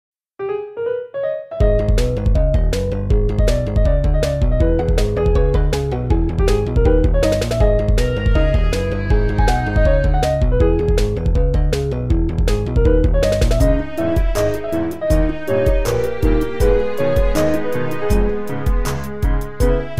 Download PVZ sound effect for free.